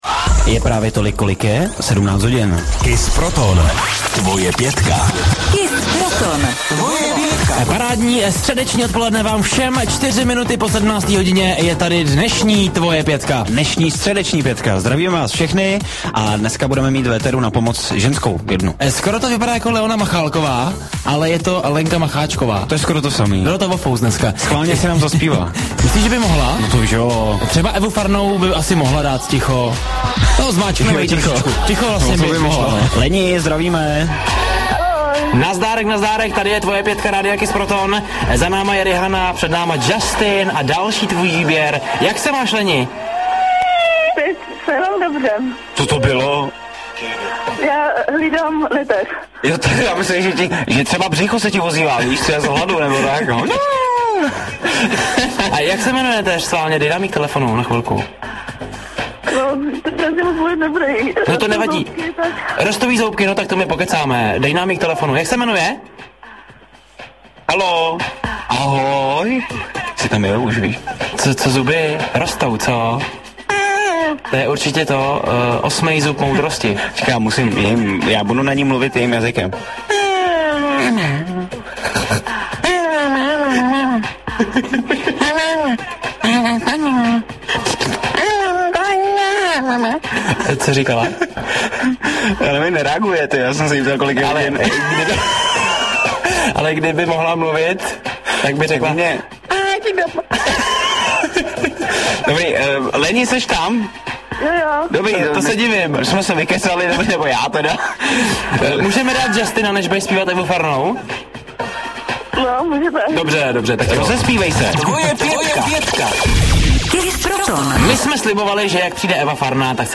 Pohotové a vtipné reakce moderátorů lze jen ocenit.
Tříhodinový maratón slovních přestřelek zvládá dvojice s přehledem. Plynulý slovní projev provází celým podvečerem a neubírá na srozumitelnosti.